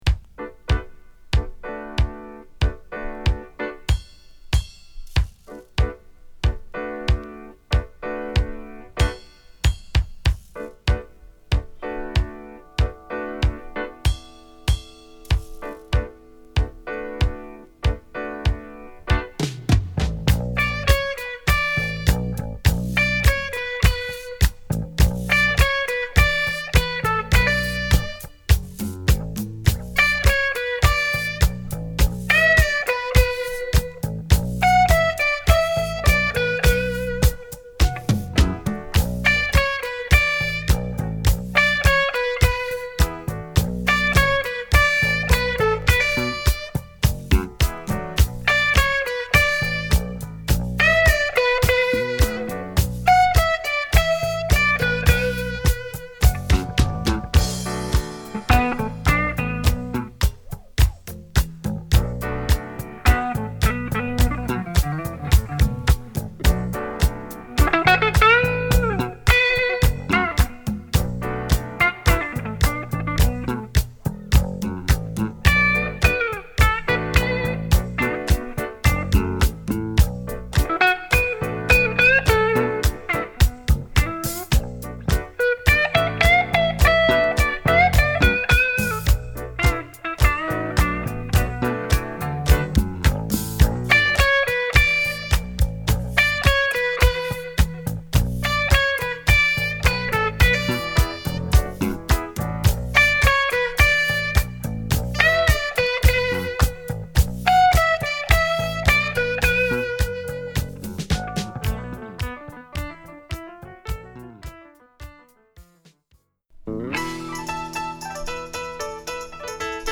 この時代らしくフュージョン色を強めた作品で